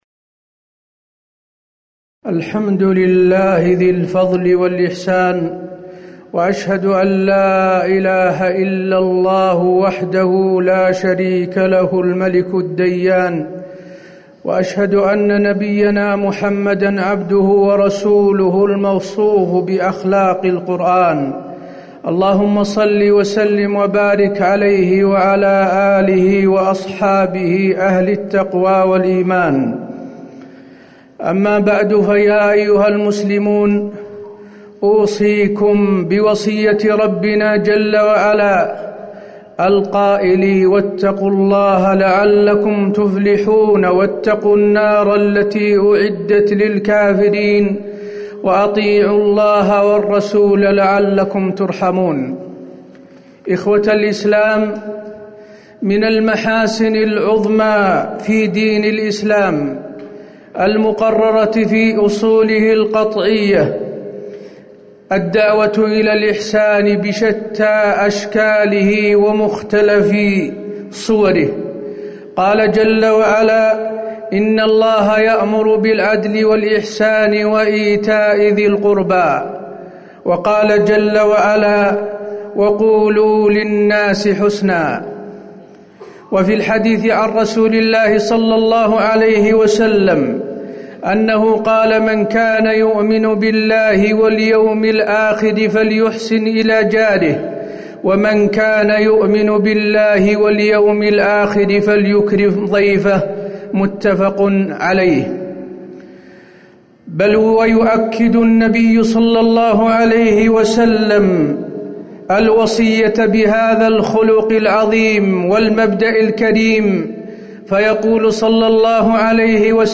تاريخ النشر ١٢ جمادى الأولى ١٤٤٠ هـ المكان: المسجد النبوي الشيخ: فضيلة الشيخ د. حسين بن عبدالعزيز آل الشيخ فضيلة الشيخ د. حسين بن عبدالعزيز آل الشيخ الإحسان في الإسلام